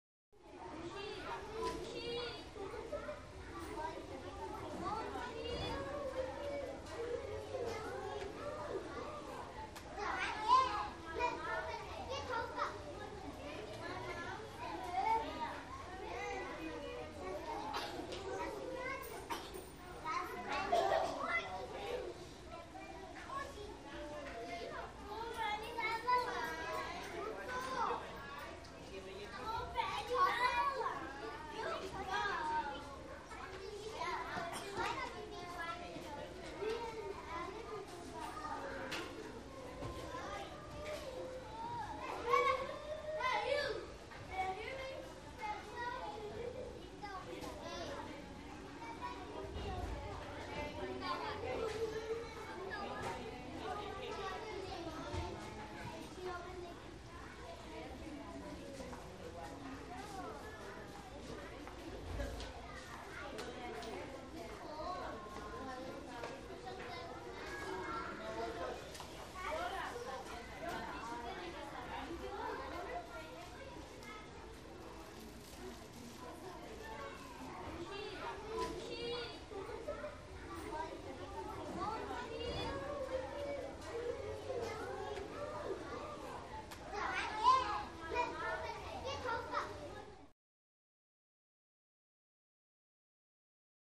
School - Kindergarten Classroom